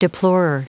Prononciation du mot deplorer en anglais (fichier audio)
Prononciation du mot : deplorer